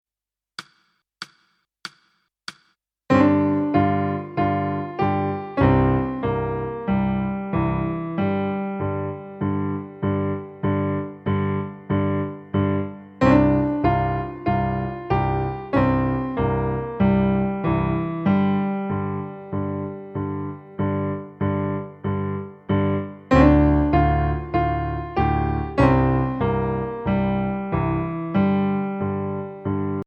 Voicing: Piano